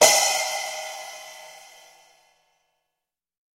На этой странице собраны веселые и яркие звуки мультяшных драк: звонкие удары, шлепки, взвизгивания и другие забавные эффекты.
Удар с временным оглушением